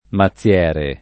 mazziere [ ma ZZL$ re ]